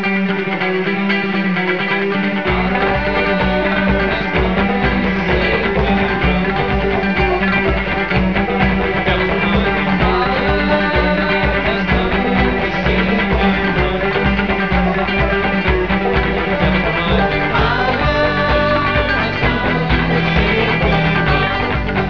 singalong chorus of the song